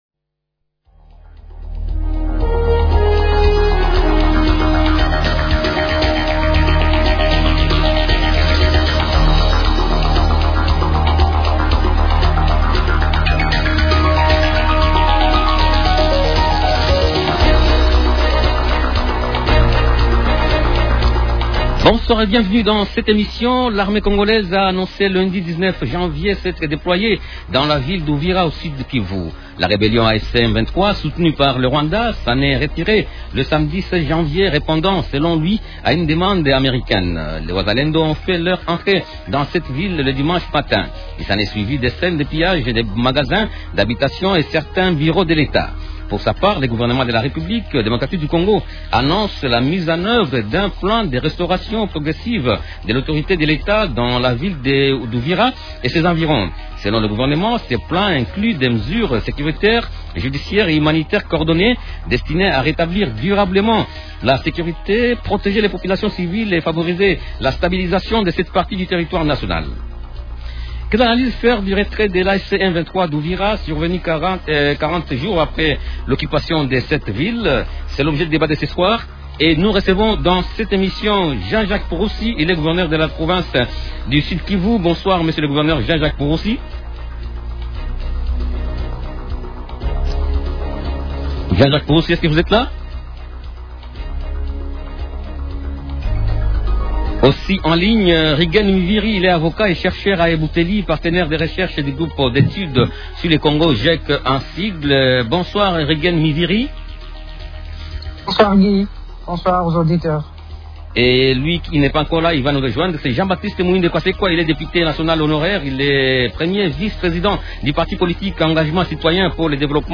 -Quelle analyse faire du retrait de l’AFC/M23 d’Uvira, survenu quarante jours après l’occupation de la ville ? Invités: -Jean-Jacques Purusi, gouverneur de la province du Sud-Kivu.